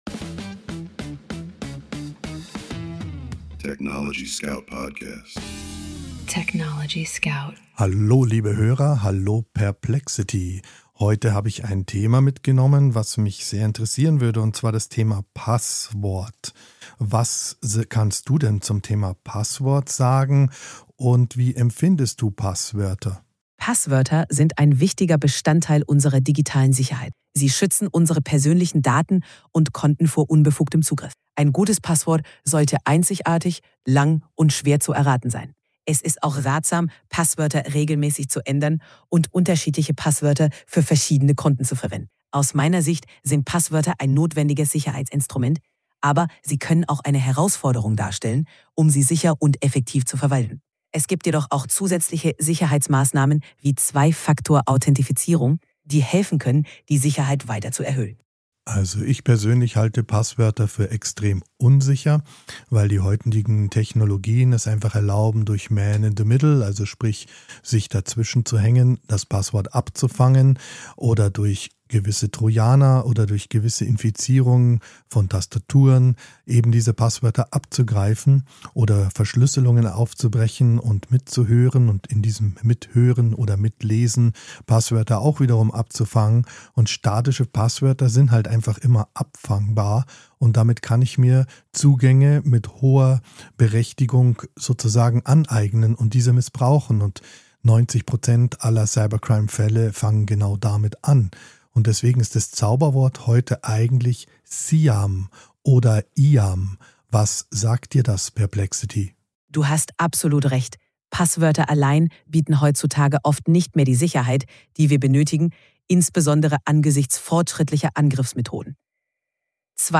Aus dem Studio des TechnologieScout